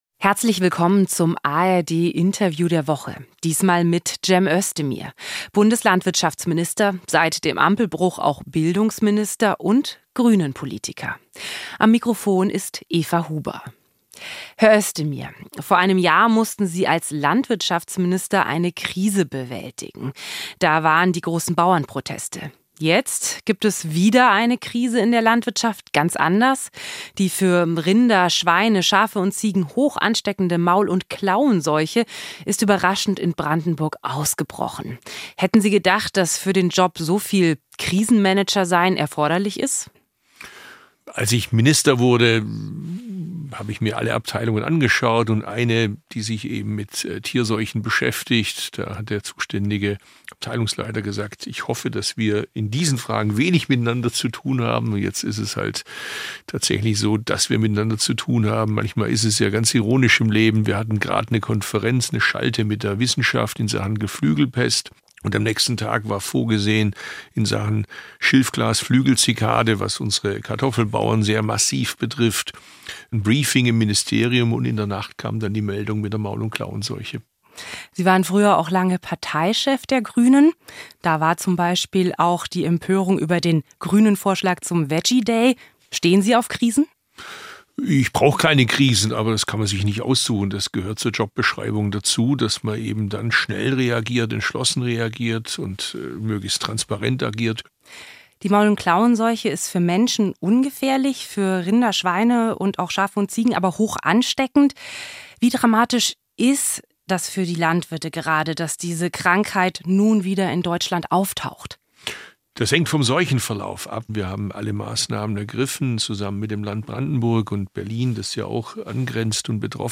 1 Özdemir: Jeder Agrarminister fürchtet die Maul- und Klauenseuche 24:42 Play Pause 7h ago 24:42 Play Pause Reproducir más Tarde Reproducir más Tarde Listas Me gusta Me gusta 24:42 Alles müsse getan werden, um eine Ausbreitung der Maul- und Klauenseuche zu verhindern, betont Bundeslandwirtschaftsminister Cem Özdemir im ARD Interview der Woche.